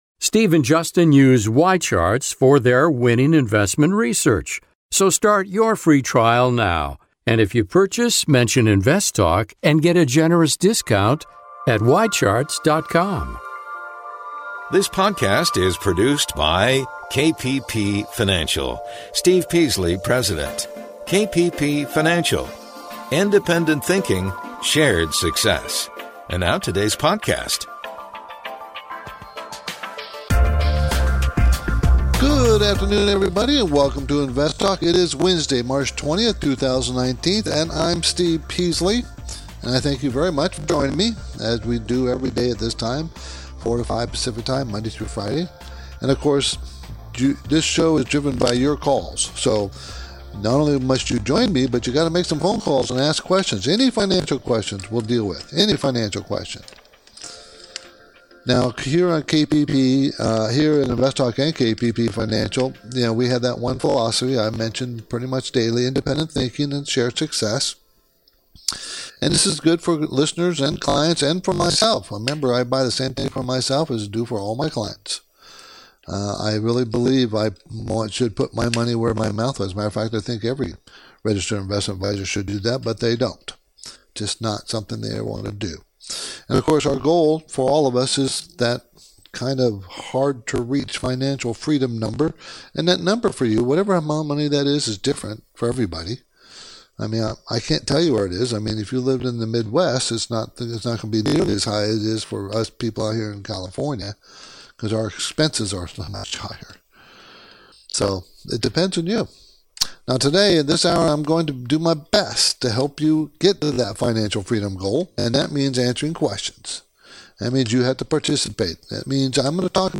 Podcast Listeners: We are experiencing an intermittent technical issue that may cause the audio stream to break-up or sound distorted.